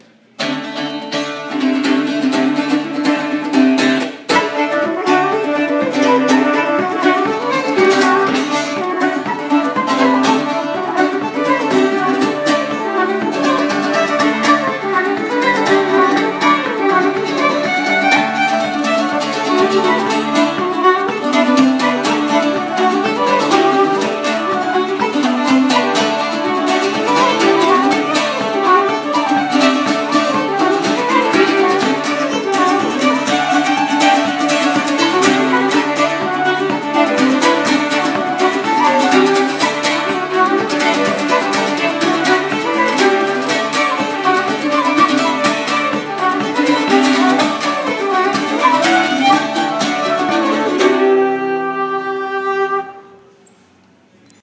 Hire Traditional Irish Musicians — The Irish Music School of Chicago
Within+a+Mile+of+Dublin+Reel+-+TRIO.m4a